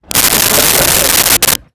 Crowd Laughing 05
Crowd Laughing 05.wav